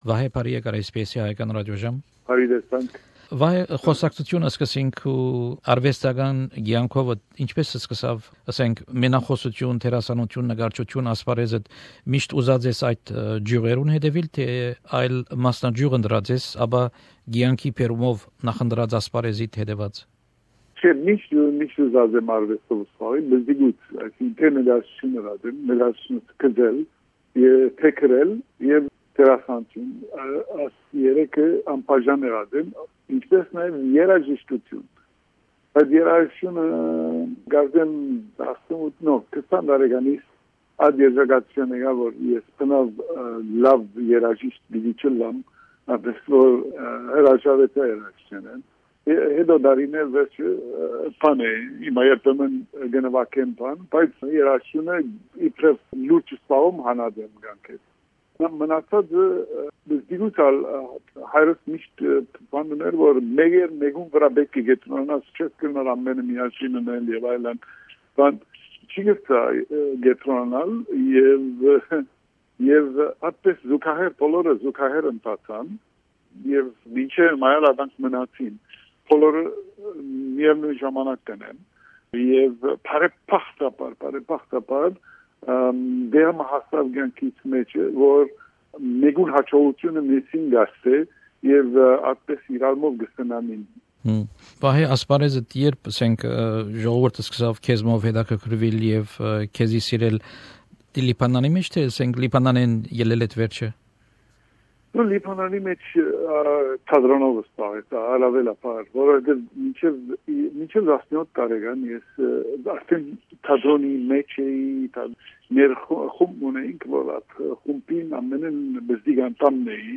Հարցազրոյց մենախօսութեան վարպետ, դերասան վիպագիր, նկարիչ, բեմադրիչ՝ Վահէ Պերպերեանի հետ: